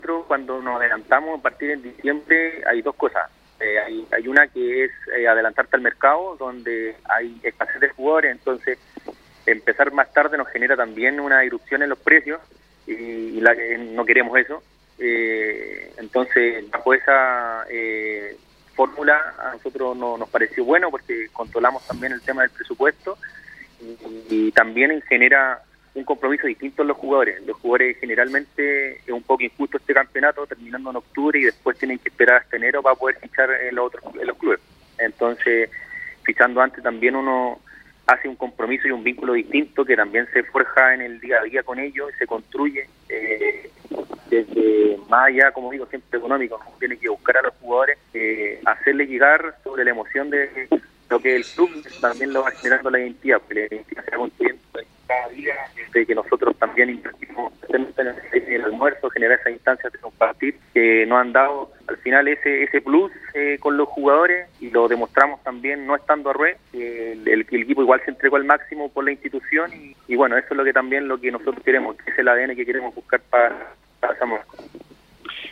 en diálogo con Radio Cappissima, explicó que esperan moverse con anticipación en materia de fichajes, para así, evitar sobreprecios.